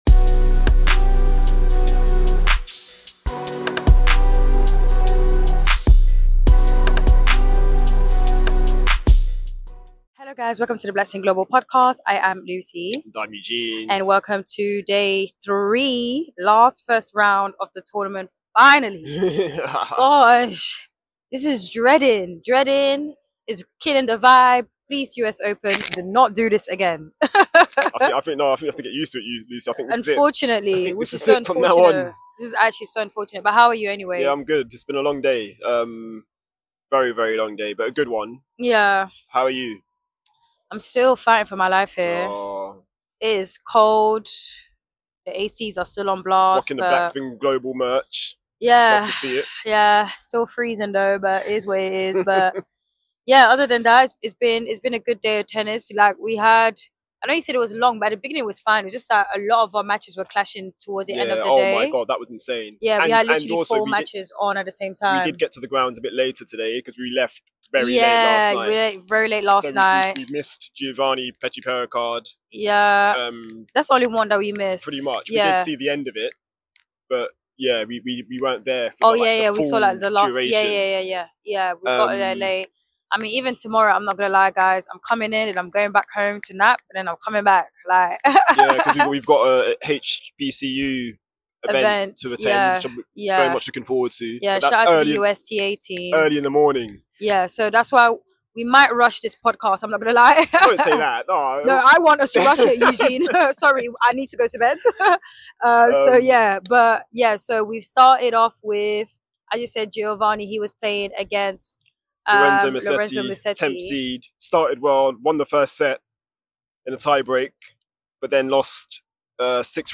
Listen out for post-match press snippets from Auger-Aliassime and Baptiste.